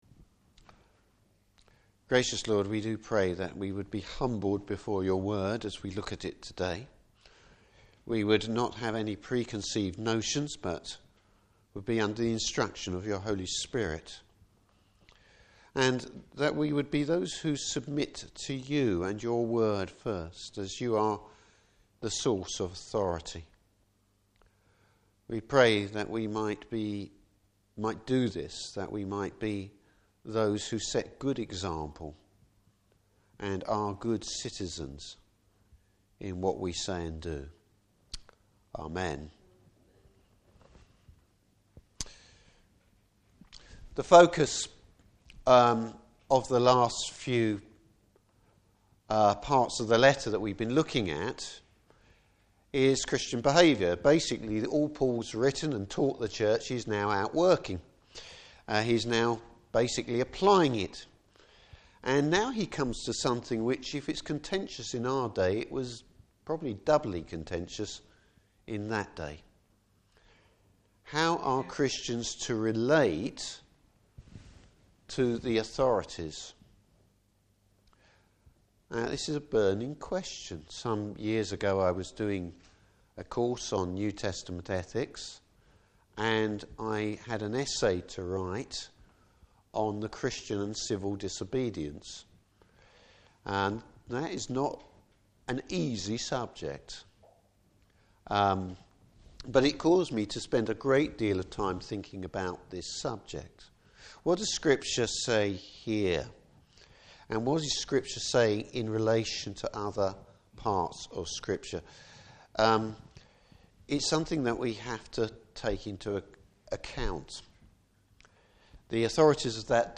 Service Type: Morning Service How should Christians relate to the civil authorities?